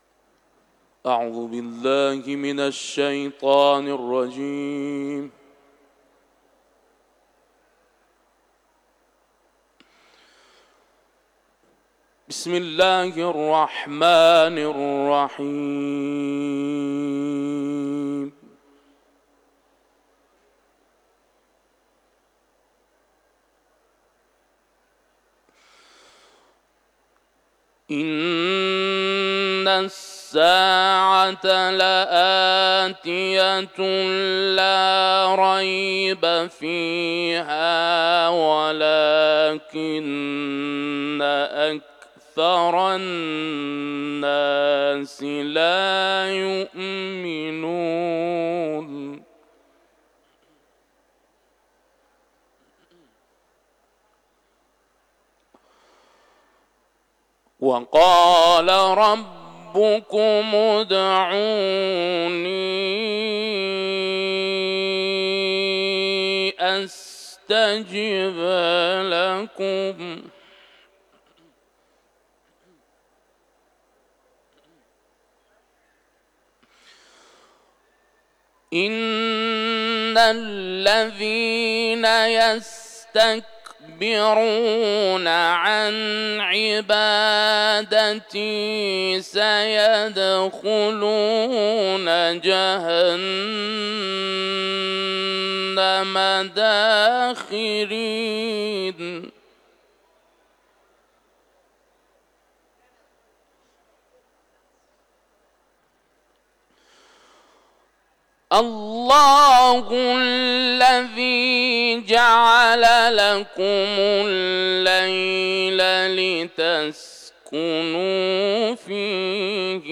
که در حرم مطهر حضرت رضا(ع) به اجرا رسیده است
تلاوت
حرم مطهر رضوی